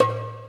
Percs